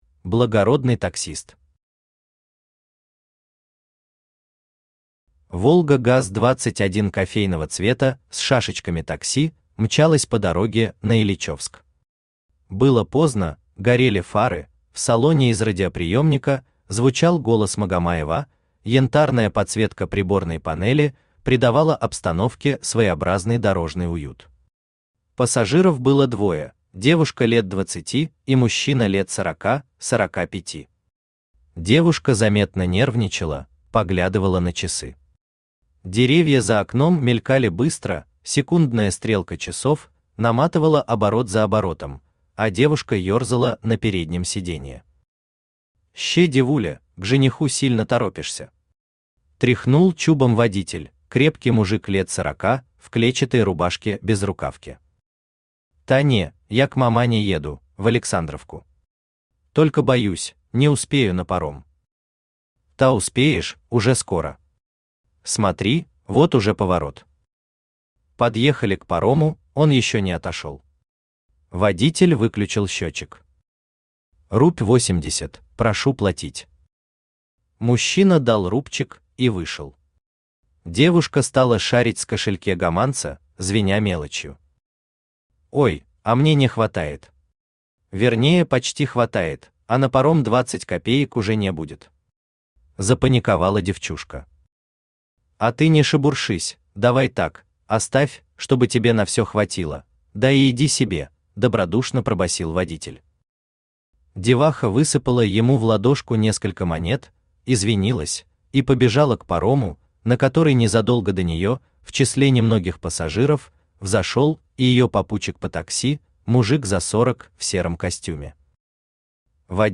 Аудиокнига Благородный таксист. Сборник одесских (и не только) рассказов | Библиотека аудиокниг
Сборник одесских (и не только) рассказов Автор Евгений Анатольевич Маляр Читает аудиокнигу Авточтец ЛитРес.